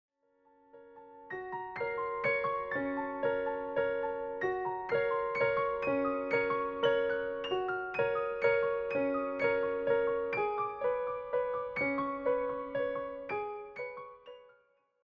presented as relaxed piano interpretations.